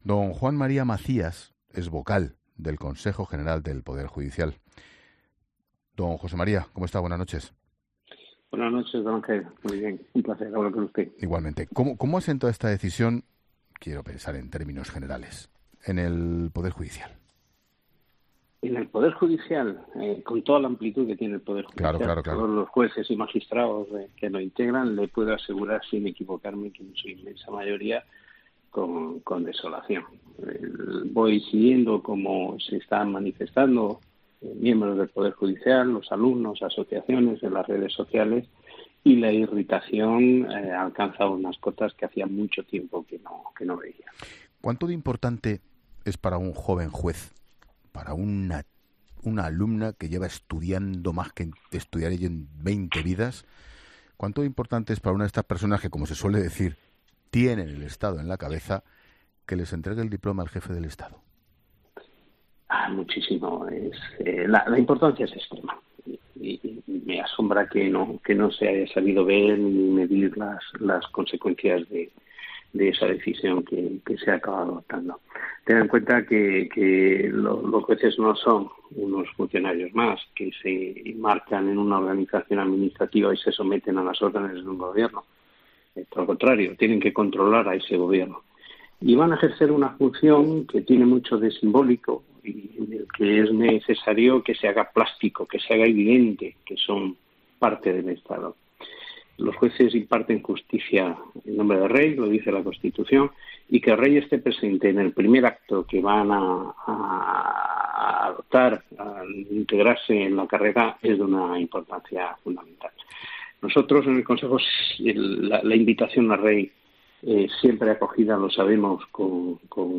Jose María Macías, vocal del CGPJ, ha pasado por los micrófonos de 'La Linterna' para valorar la ausencia del monarca en la entrega de despachos a los nuevos jueces en Barcelona
Macías ha pasado por los micrófonos de ‘La Linterna’ con Ángel Expósito.